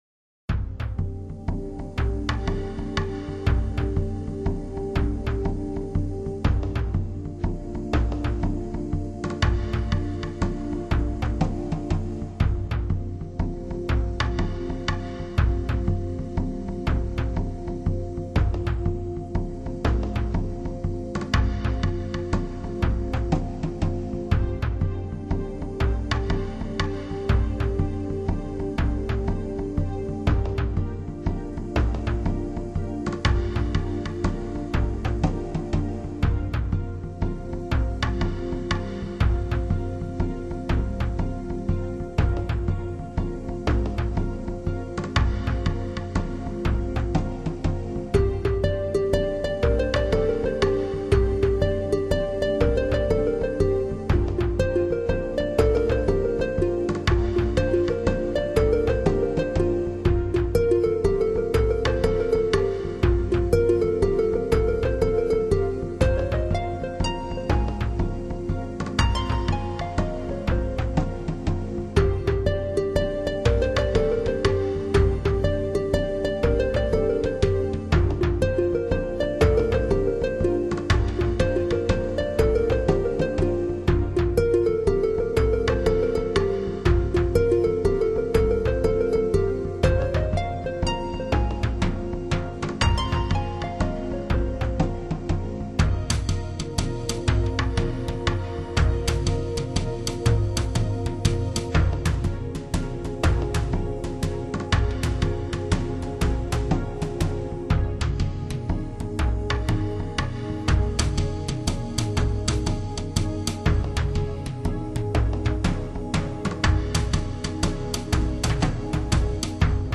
HIFI纯音乐发烧碟
很有节奏感．